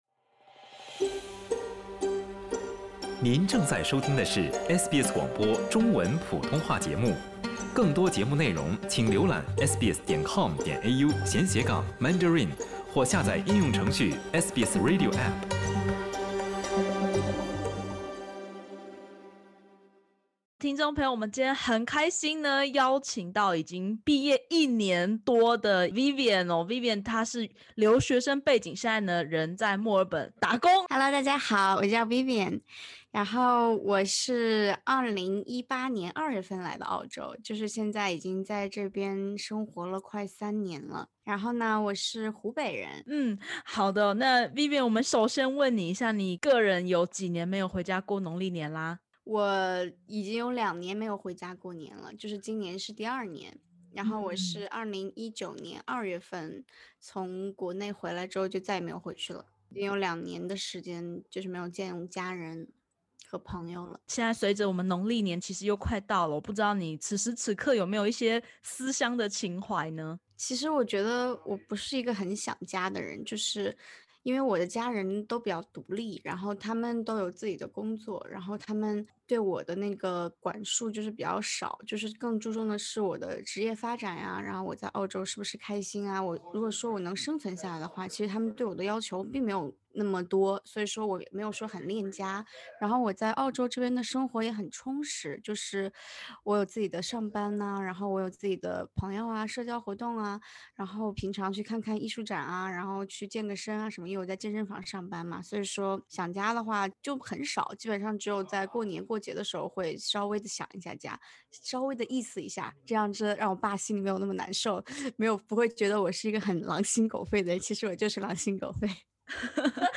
點擊首圖收聽寀訪音頻。